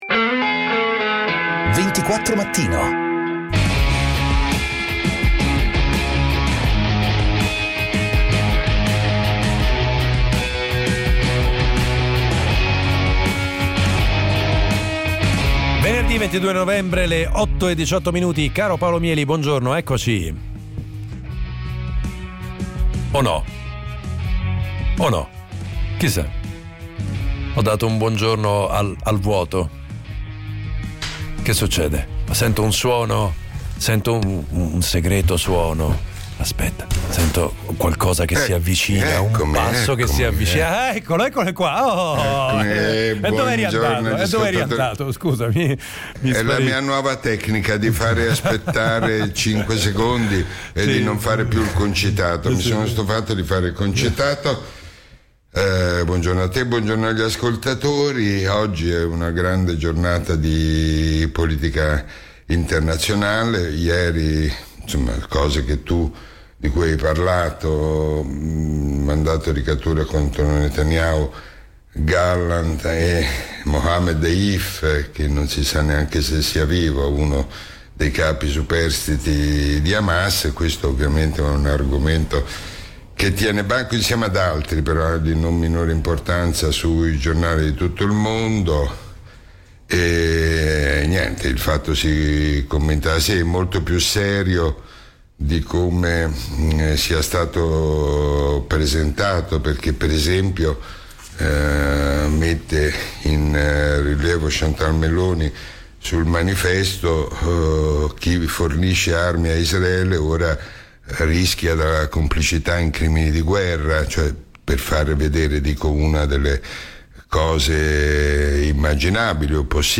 24 Mattino - Le interviste
Prima l'imperdibile appuntamento quotidiano con Paolo Mieli per commentare i fatti della giornata. Poi gli ospiti dal mondo della politica, dell'economia, della cronaca, della giustizia: i protagonisti dei fatti o semplicemente chi ha qualcosa di interessante da dire o su cui riflettere, compresi gli ascoltatori.